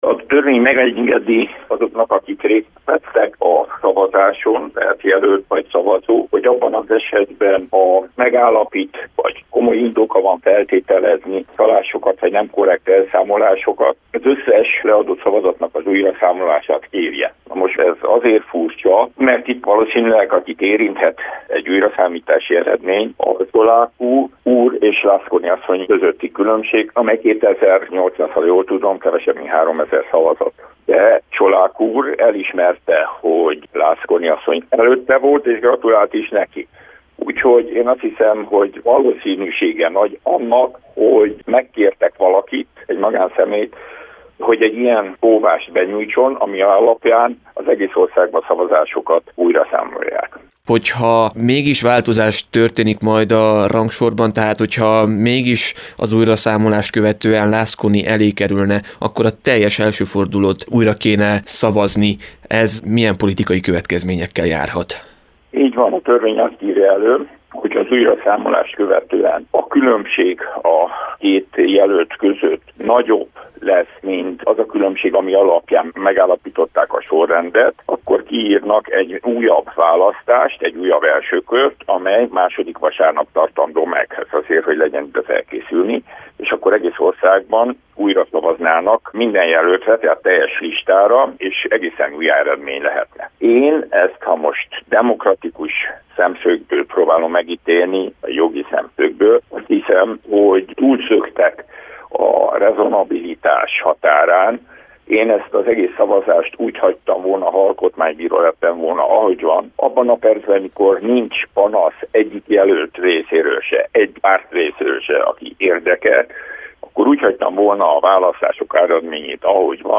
Mivel magyarázható az államelnök-választás első fordulóján leadott szavazatok újraszámolása, hiszen eredetileg egyetlen körzetből sem érkezett panasz? – kérdeztük Frunda György ügyvédet, akivel a választások körül kialakult helyzet lehetséges forgatókönyveit is elemeztük.